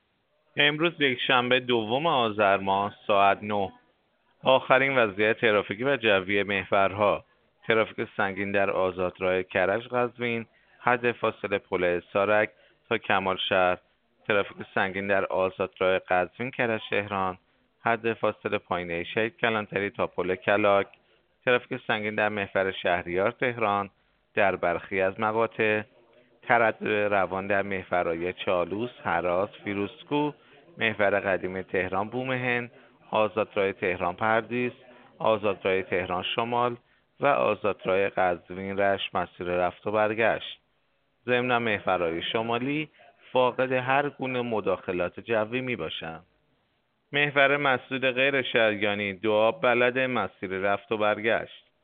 گزارش رادیو اینترنتی از آخرین وضعیت ترافیکی جاده‌ها ساعت ۹ دوم آذر؛